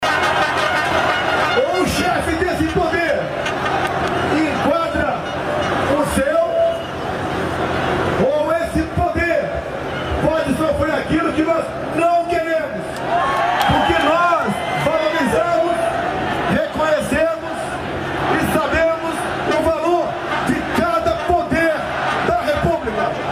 Durante manifestações de Brasília na manhã desta terça-feira (07), o presidente Jair Bolsonaro cobrou o Presidente do Supremo Tribunal Federal, Luiz Fux, sem citar o nome do Ministro, a enquadrar a corte, afirmando que, se isso não ocorrer, o Poder Judiciário pode “sofrer aquilo que nós não queremos”.